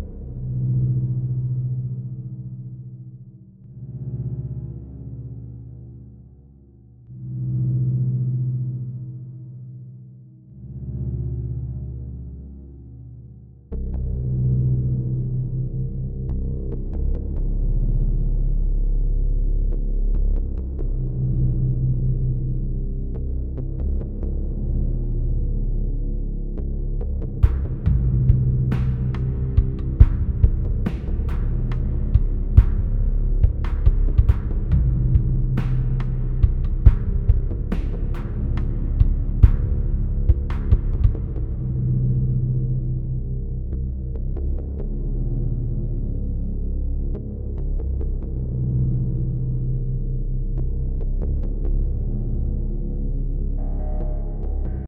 A little dark loop ambient music :)